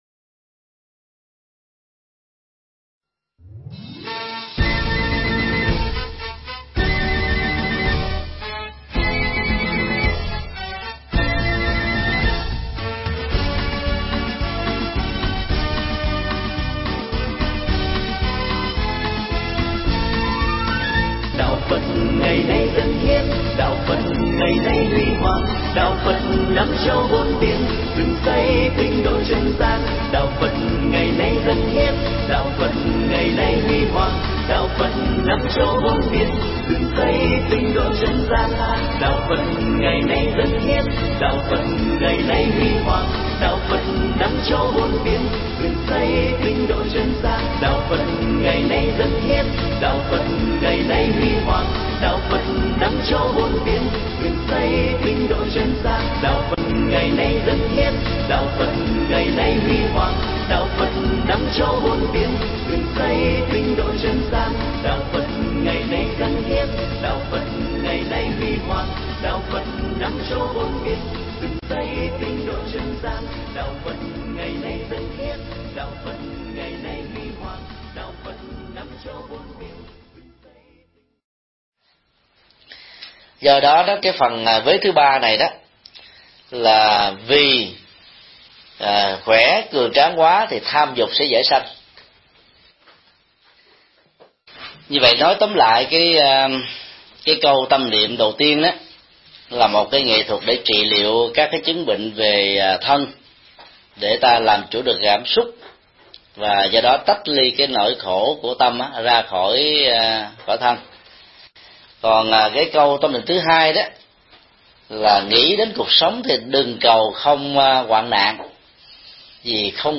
Mp3 Pháp thoại Mười điều tâm niệm – điều 2: Tu trong hoạn nạn
giảng tại Đạo tràng Quảng Từ, San Jose